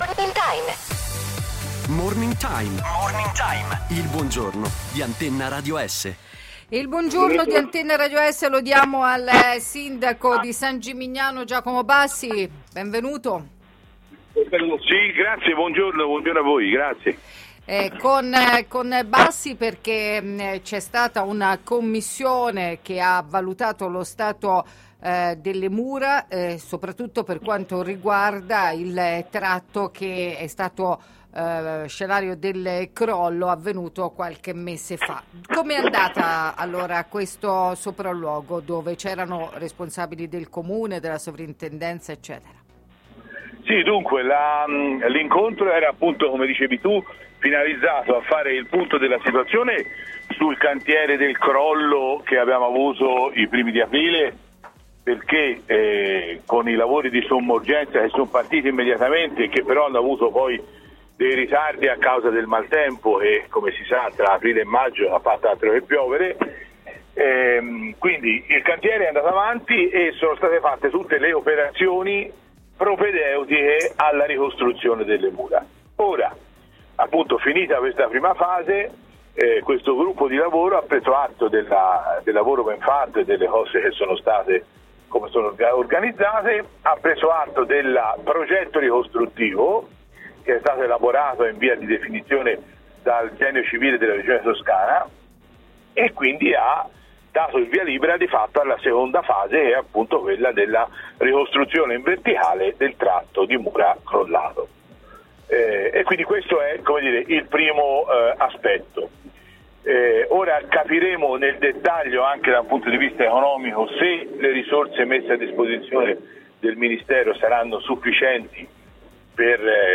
Interviste
San Gimignano, inizia la ricostruzione delle mura: il sindaco Giacomo Bassi 6 Luglio 2018